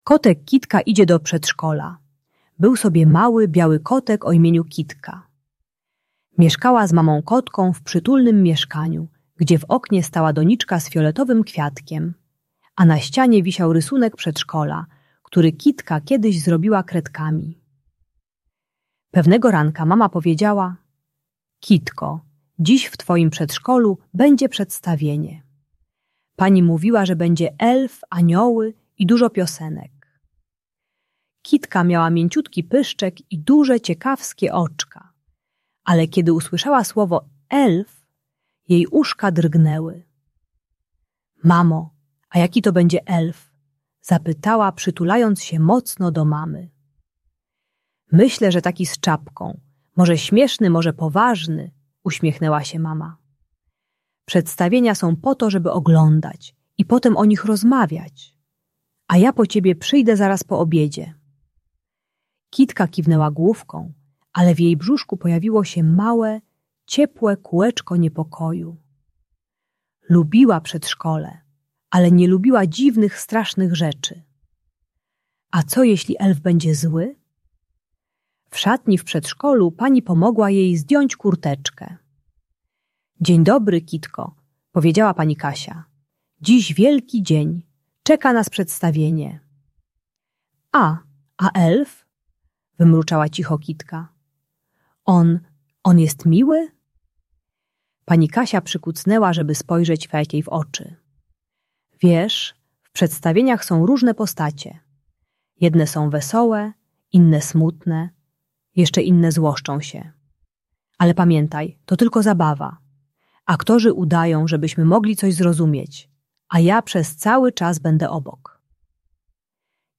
Audiobajka o lęku i wycofaniu u wrażliwych dzieci.